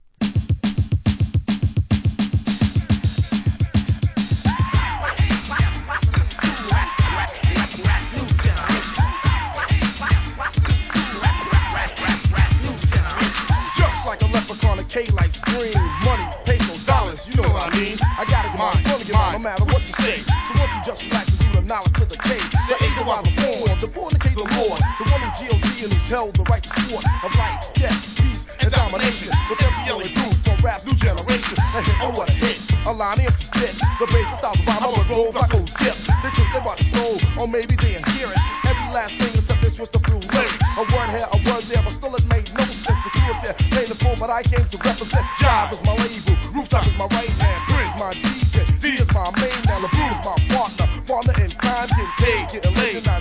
HIP HOP/R&B
JB使いの1988年ミドル!!!